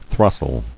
[thros·tle]